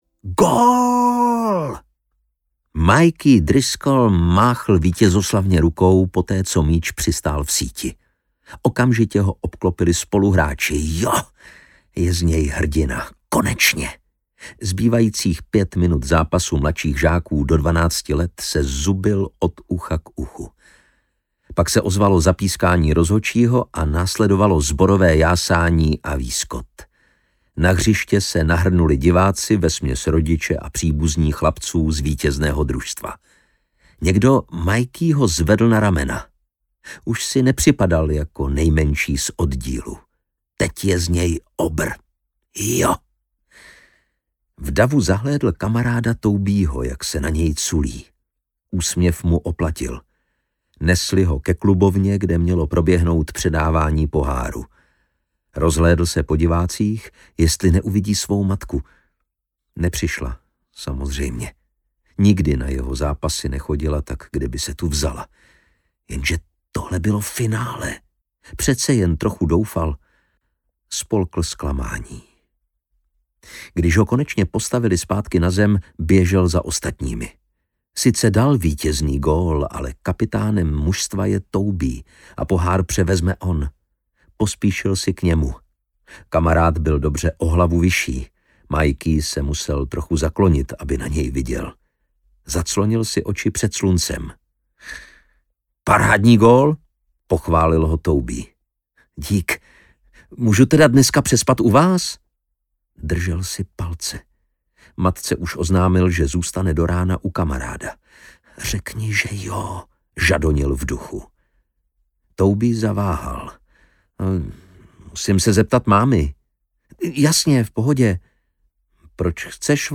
Audiokniha Dokonalá smrt – třetí díl drsné krimi série s detektivem Lucem Callanachem a inspektorkou Avou Turnerovou.
Čte Jan Šťastný.